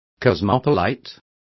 Complete with pronunciation of the translation of cosmopolite.